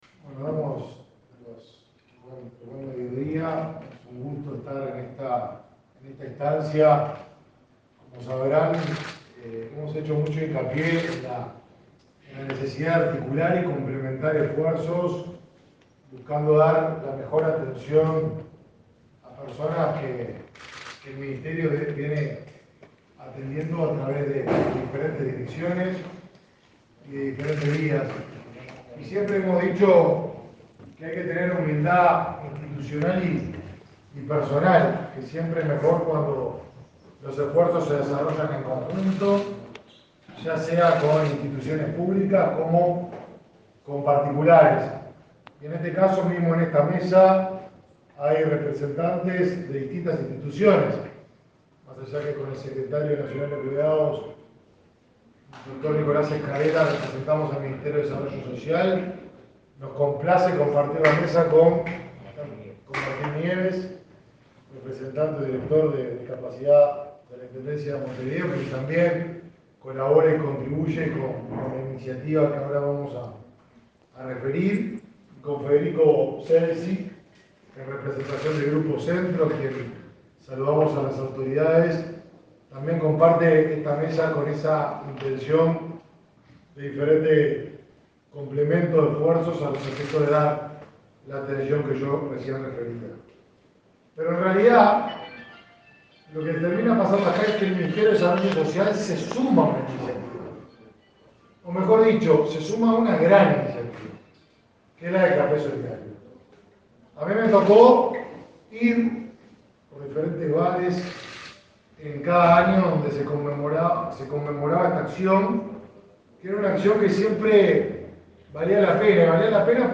Conferencia de prensa por campaña solidaria para participantes de programas del Mides